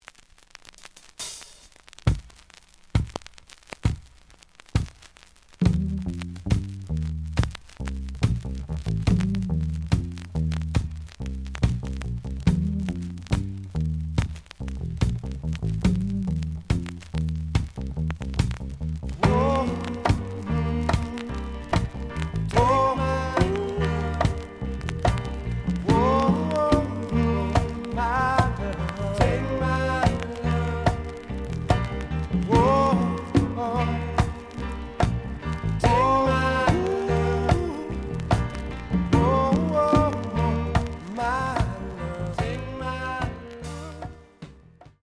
The audio sample is recorded from the actual item.
●Genre: Soul, 70's Soul
Some noise on B side.